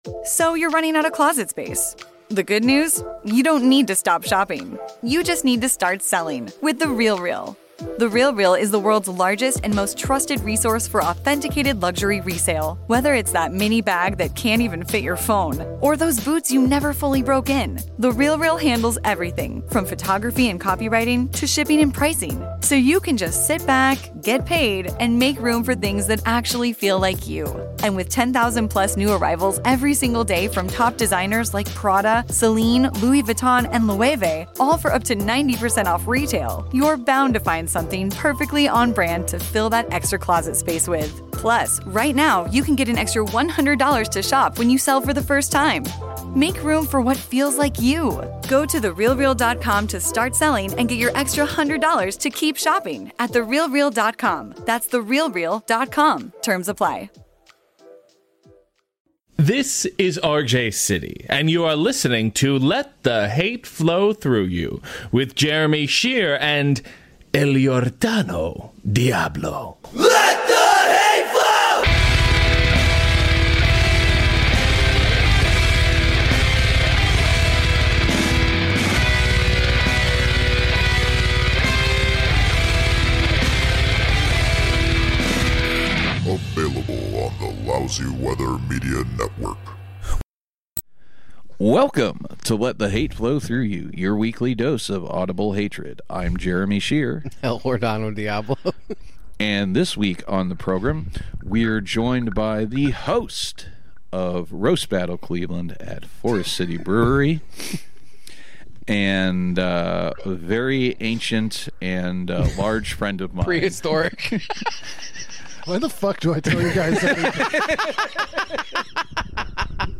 Two fats and a tall discuss bad parenting while being the living embodiment of too much freedom given as a child.